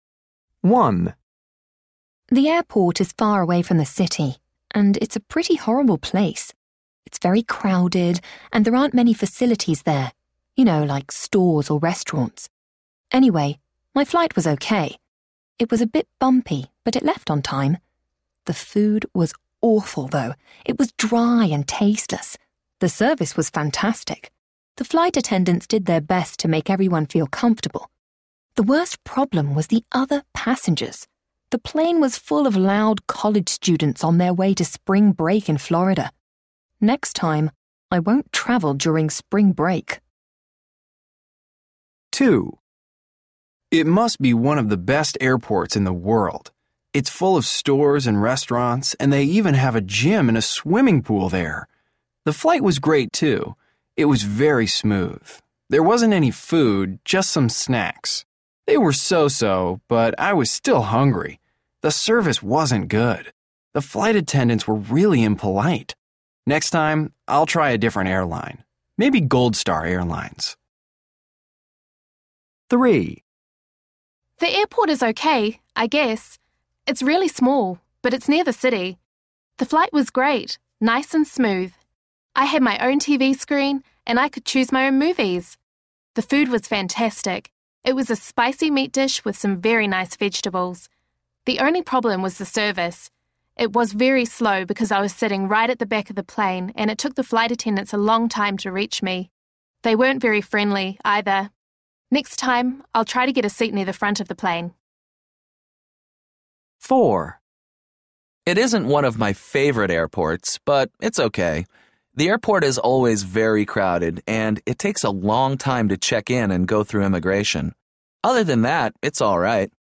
A. People are talking about flights.